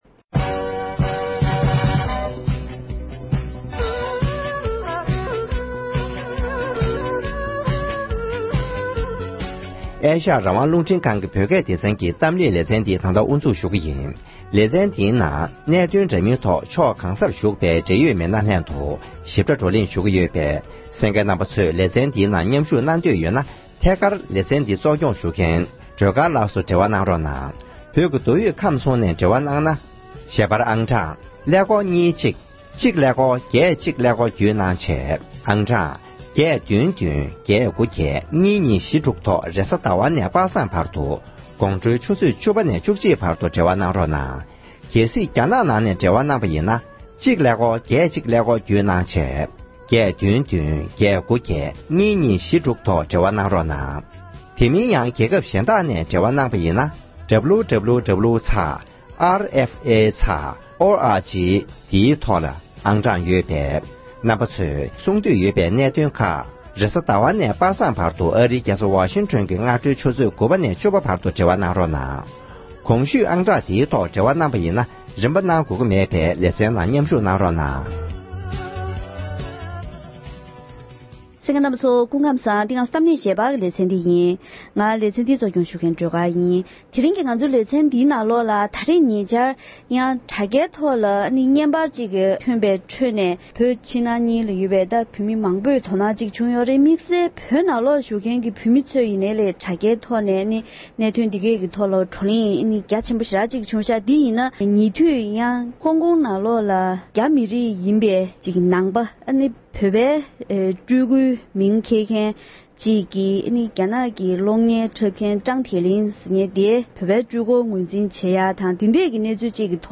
༄༅། །དེ་རིང་གི་གཏམ་གླེང་ཞལ་པར་ལེ་ཚན་ནང་ཉེ་ཆར་དྲ་རྒྱའི་ཐོག་ནས་ཐོན་པའི་ཧོང་ཀོང་གི་སྤྱི་ཚོགས་ནང་ཆོས་རྗེ་རྫུན་མ་དང་སྤྲུལ་སྐུ་རྫུན་མའི་དོན་རྐྱེན་འདིའི་ཐོག་ནས་བོད་བརྒྱུད་ནང་བསྟན་ལ་སློབ་གཉེར་དང་དོ་སྣང་ཡོད་མཁན་རྒྱ་མིའི་སྤྱི་ཚོགས་དང་བོད་ནང་ལ་ཤུགས་རྐྱེན་ཇི་བྱུང་སྐོར་འབྲེལ་ཡོད་མི་སྣ་དང་ལྷན་བཀའ་མོལ་ཞུས་པ་ཞིག་གསན་རོགས་གནང་།